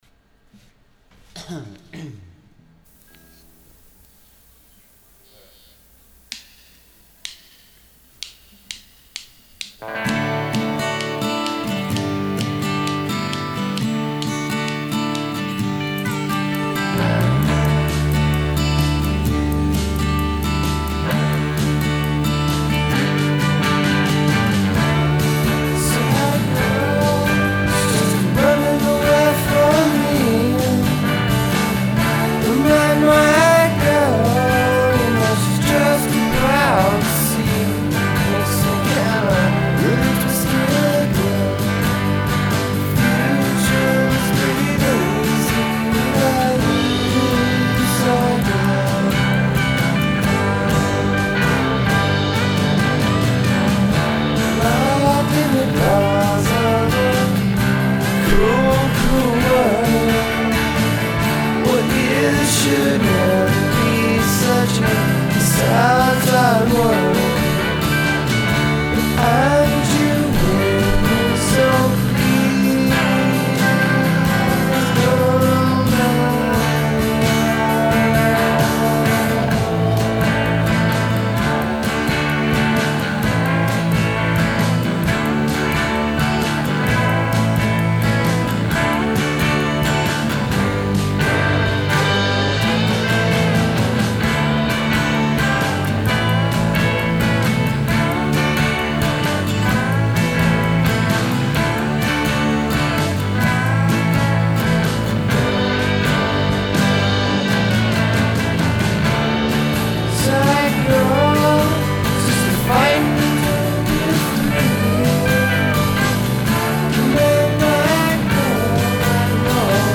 demo #1 . 2001
drums, lead guitar, bass
rhythm guitar, vocals, some lead guitar